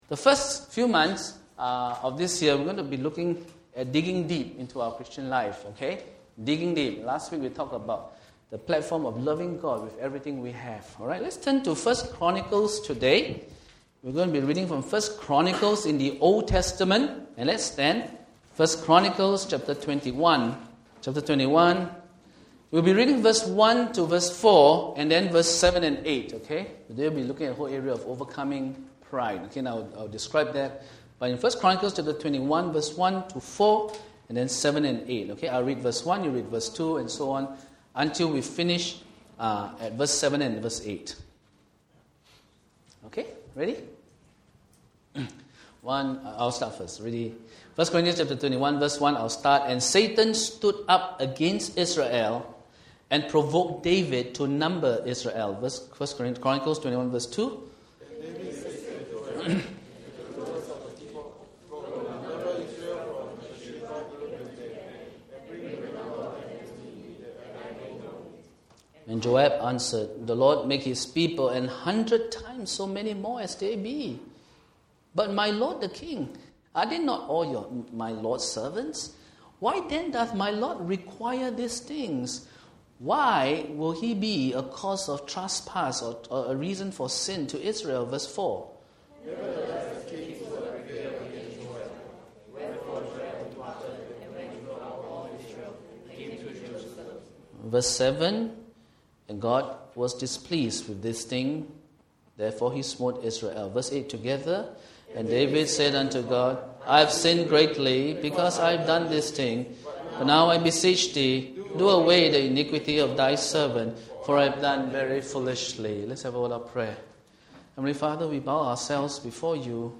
Sunday Worship Service